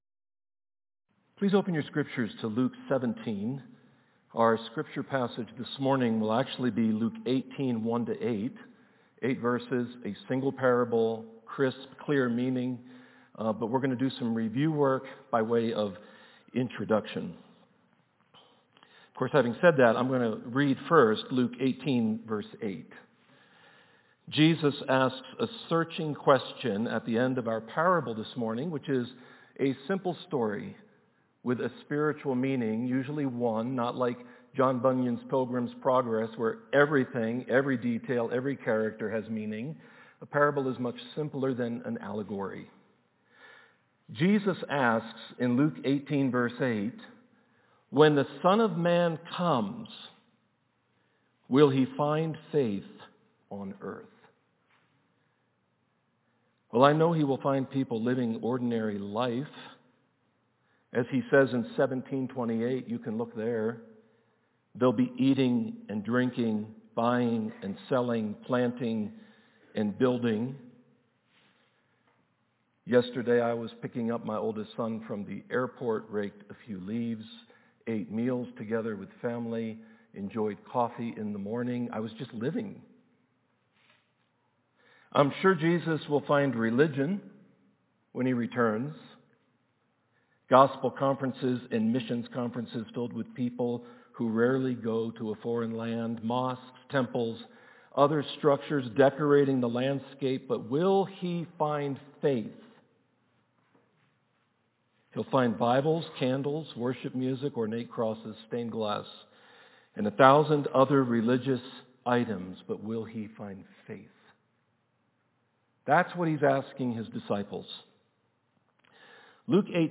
Sermons
Service: Sunday Morning In Luke 20:9-19 Jesus tells a parable of a master who owns a vineyard, cares for it, and leases it to tenants.